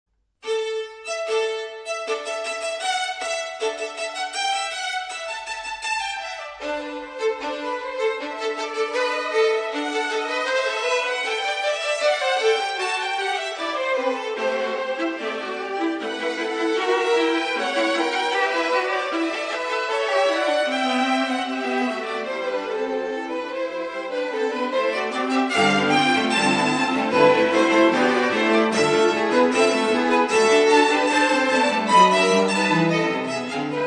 per Archi